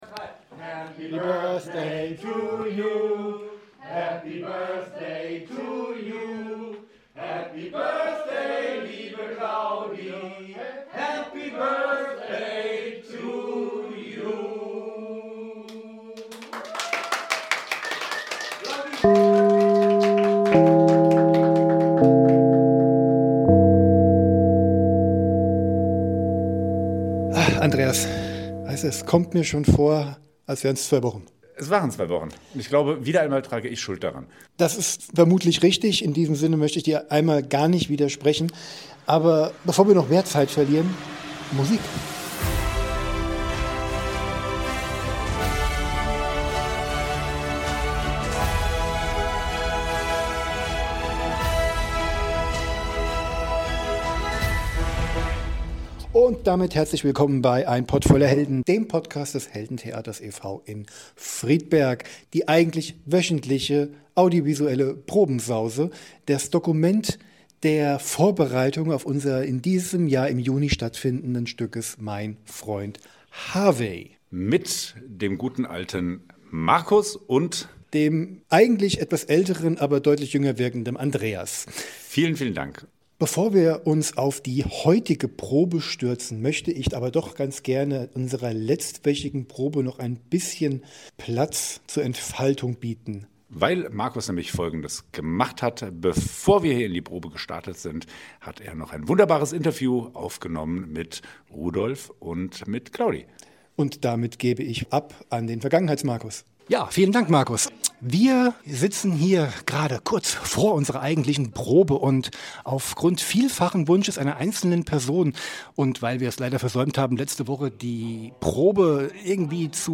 Ganz einfach: 3 Interviewpartner einladen.